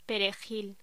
Locución: Perejil
voz